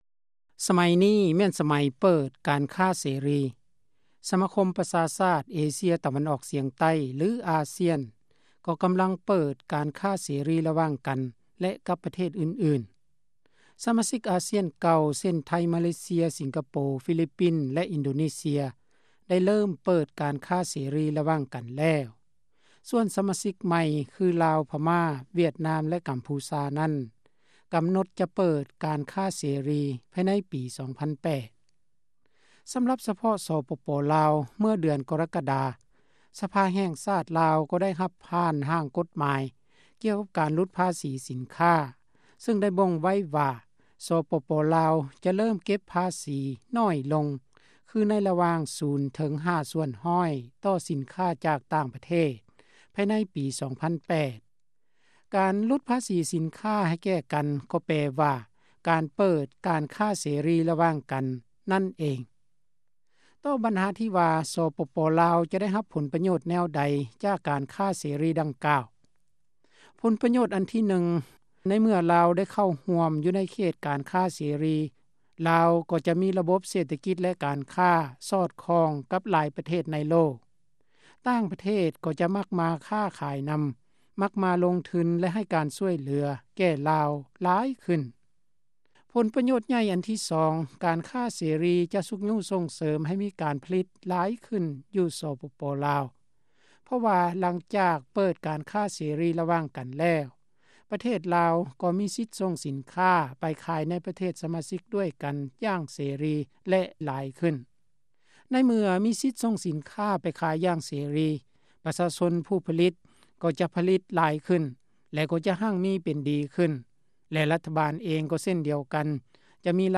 ບົດວິເຄາະ